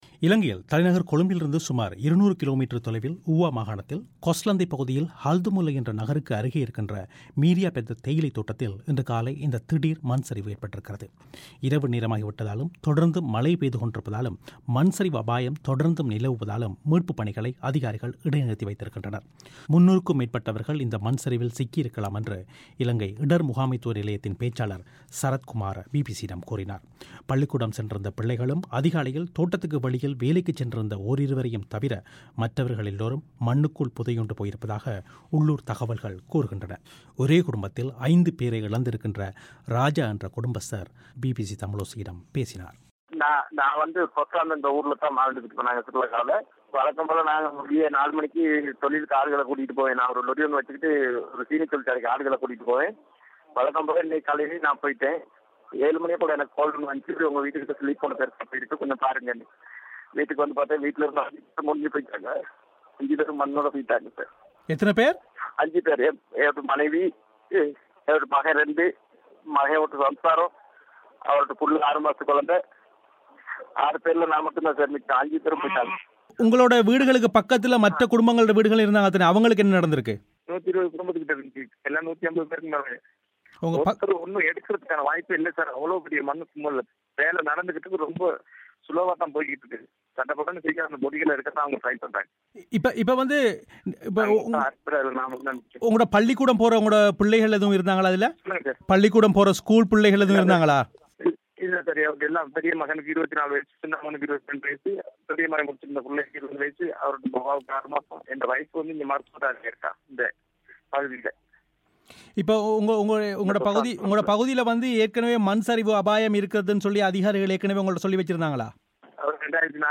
ஏற்கனவே மண்சரிவு அபாயம் உள்ள பிரதேசங்கள் என்று அறிவிக்கப்பட்ட பகுதிகளில் உள்ள மக்களுக்கு ஏன் மாற்றுக் குடியிருப்புகள் வழங்கவில்லை என்று இலங்கையின் இடர் முகாமைத்துவ அமைச்சர் மகிந்த அமரவீரவிடம் பிபிசி கேள்வி எழுப்பியது.